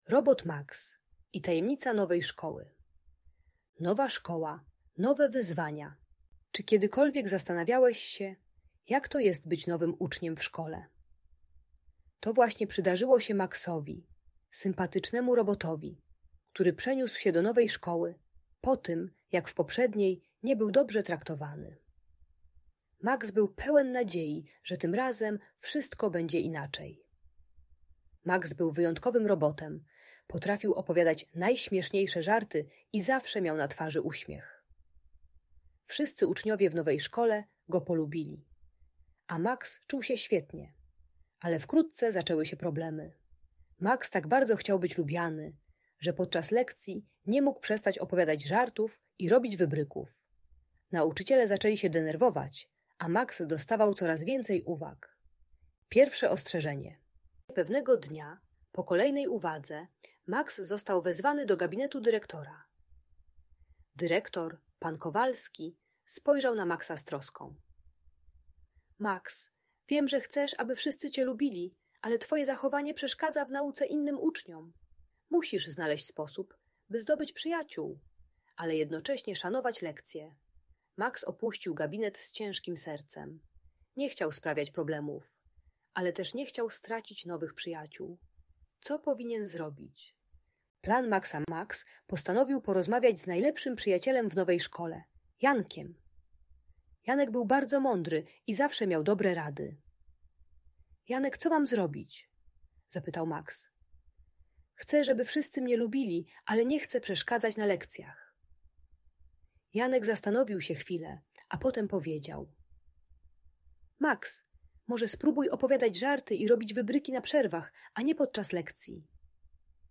Audiobajka o zachowaniu w szkole uczy techniki "czas i miejsce" - rozróżniania kiedy można żartować (przerwa), a kiedy trzeba się skupić (lekcja).